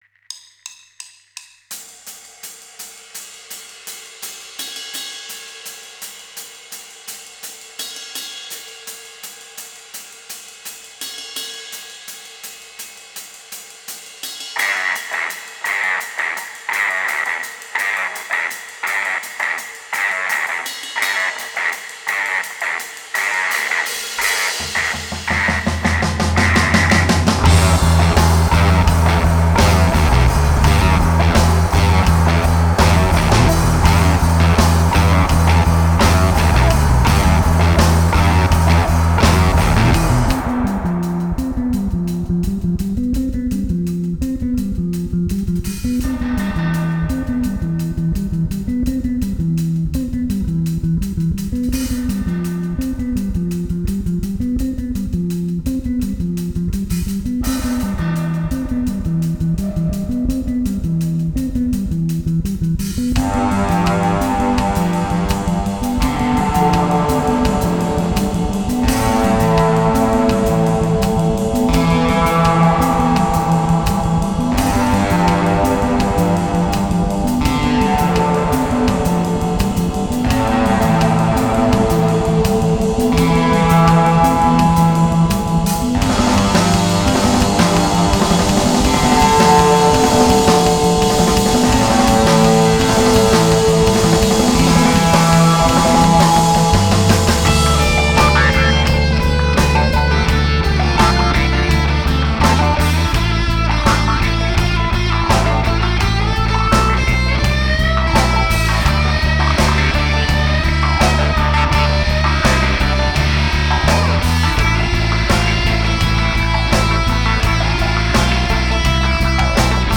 bass, guitar and drums.